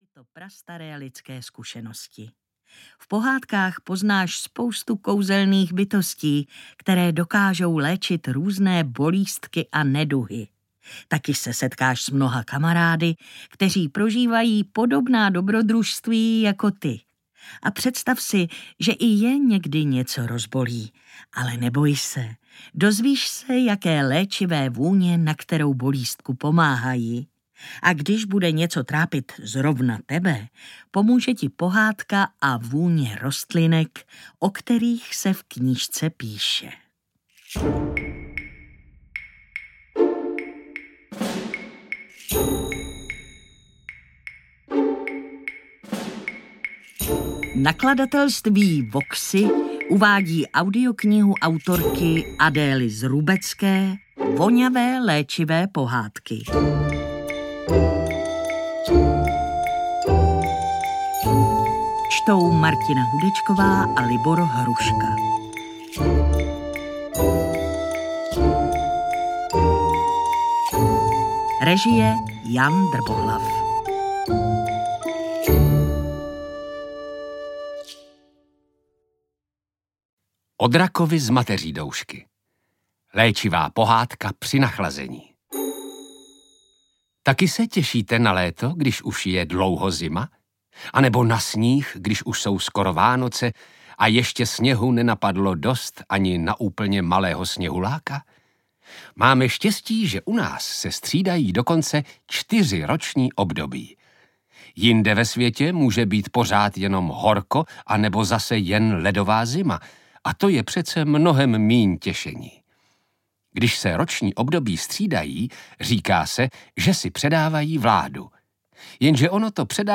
Voňavé léčivé pohádky audiokniha
Ukázka z knihy